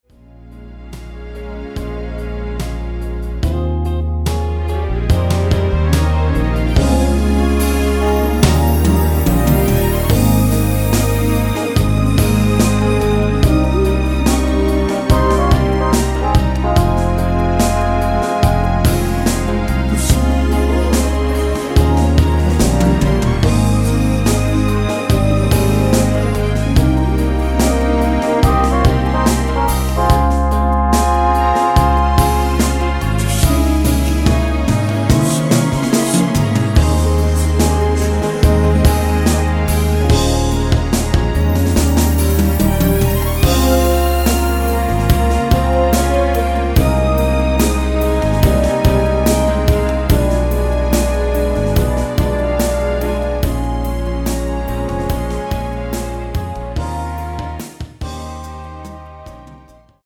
(-2)내린 코러스 포함된 MR 입니다.(미리듣기 참조)
Ab
앞부분30초, 뒷부분30초씩 편집해서 올려 드리고 있습니다.
중간에 음이 끈어지고 다시 나오는 이유는